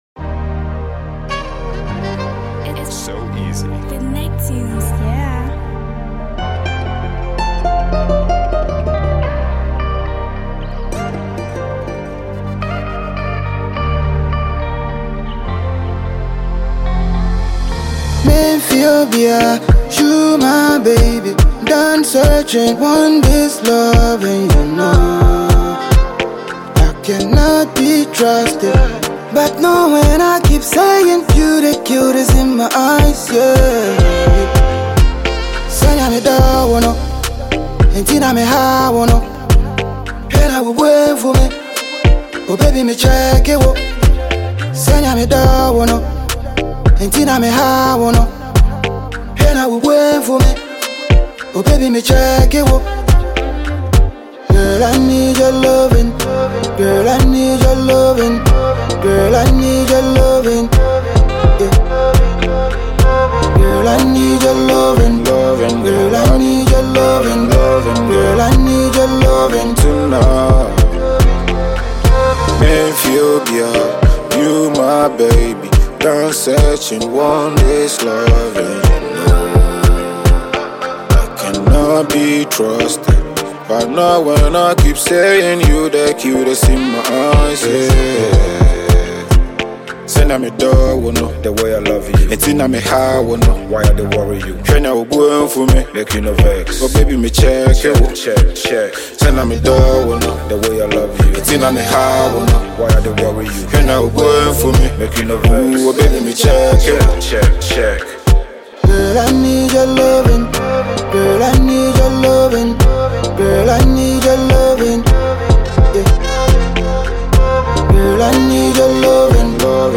Ghanaian Music Duo